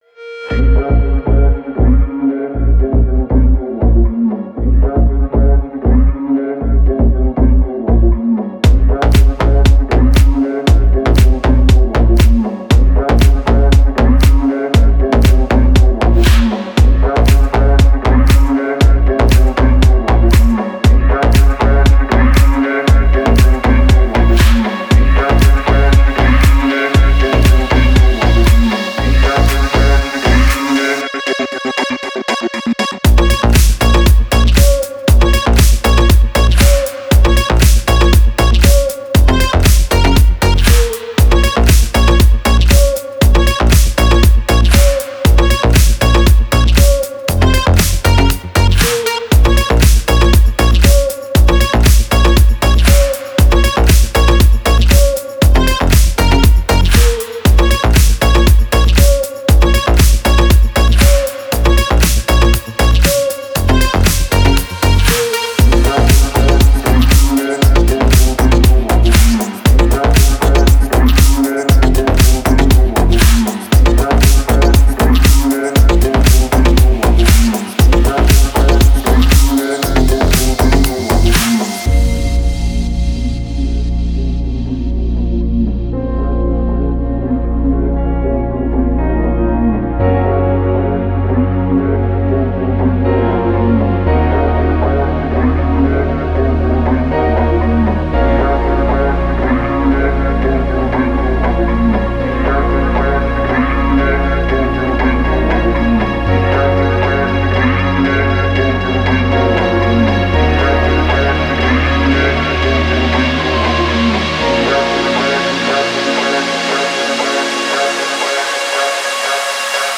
Музыка для тренировок